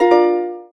kling.wav